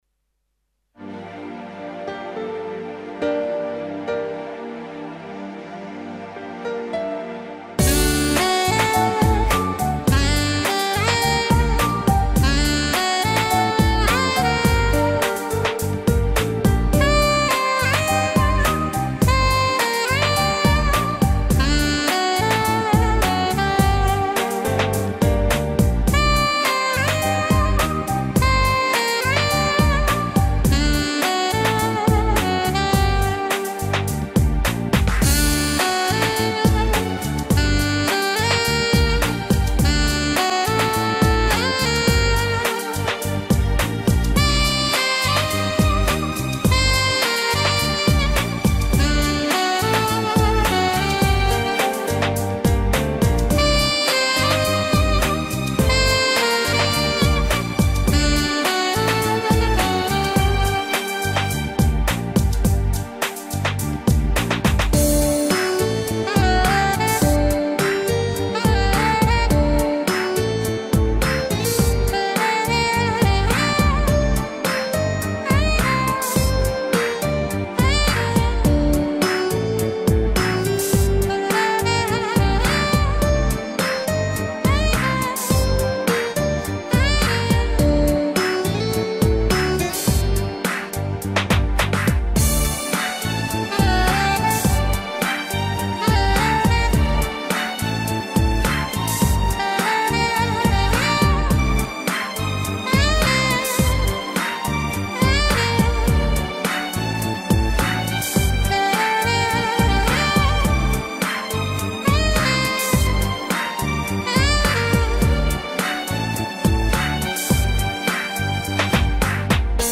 Мне понравился инструментал!